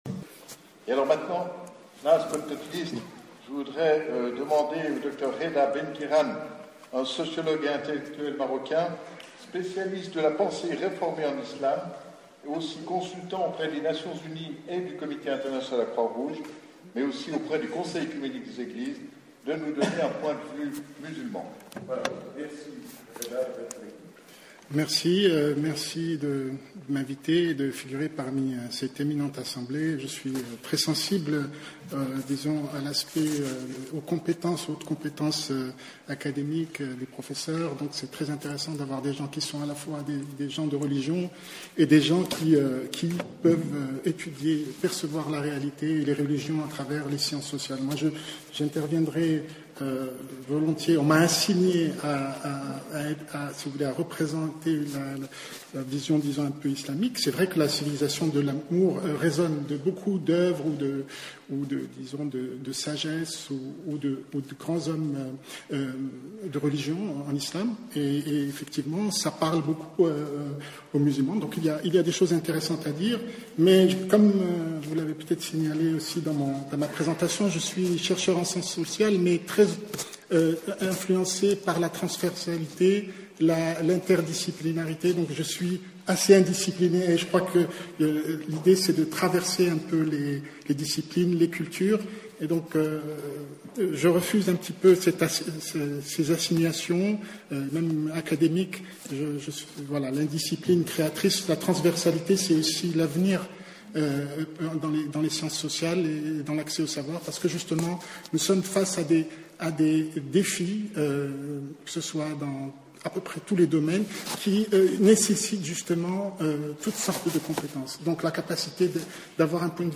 30 octobre 2014, Bibliothèque des Nations Unies, Palais des Nations, Genève